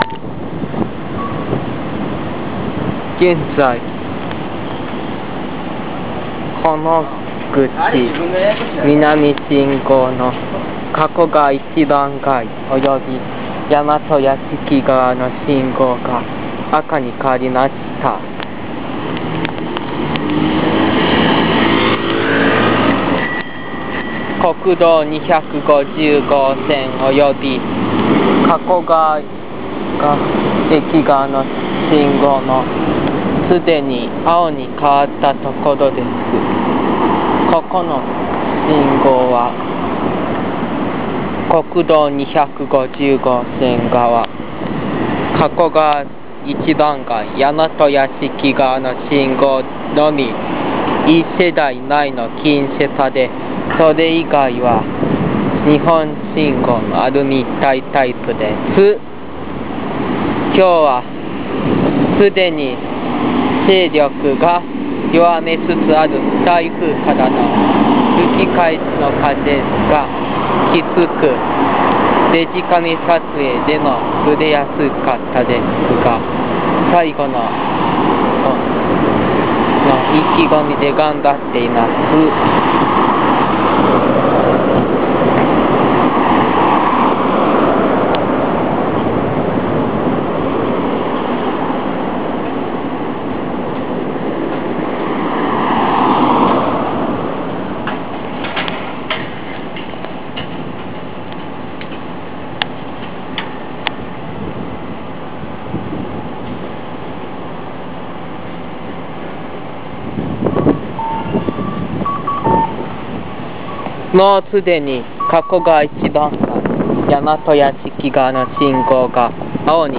All Nomal 加古川駅周辺録音。